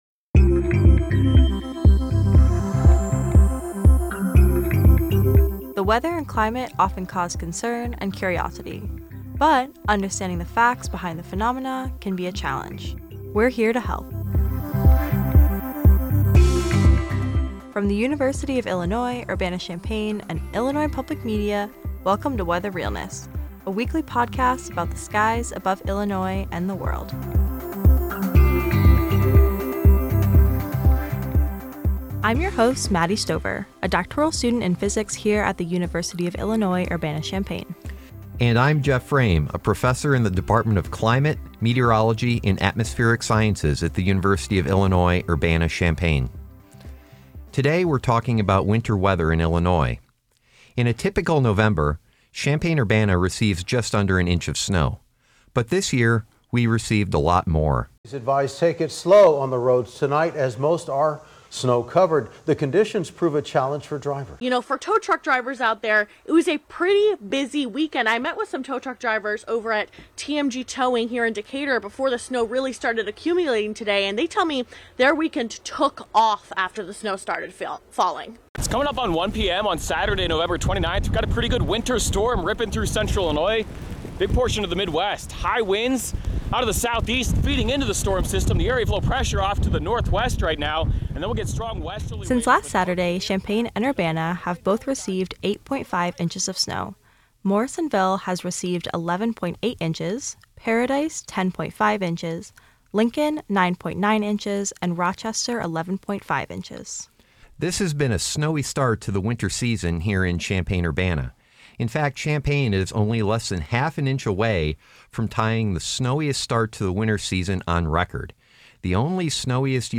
Co-hosts